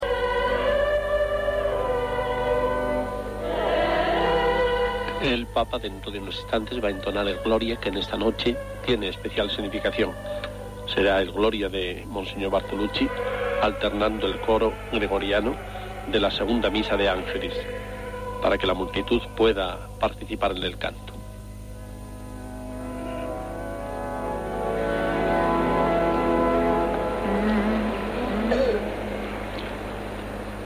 Transmissió de la missa del gall des de la basílica de Sant Pere a la Ciutat del Vaticà